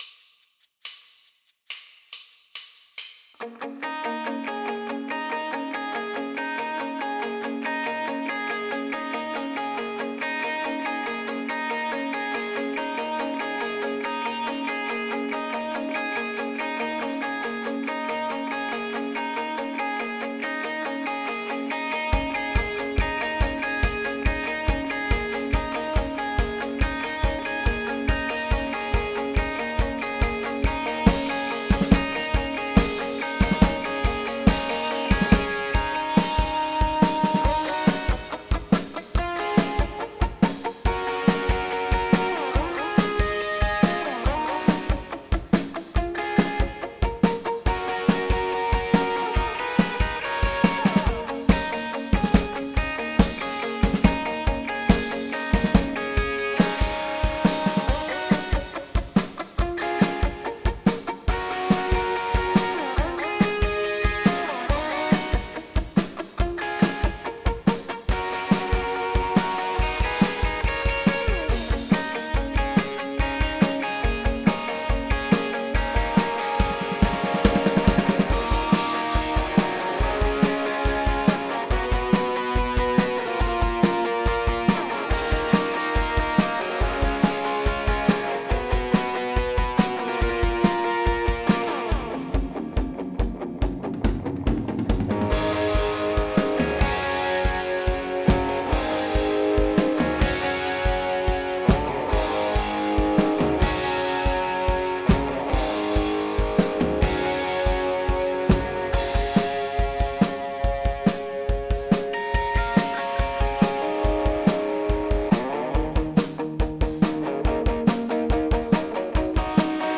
Without vocals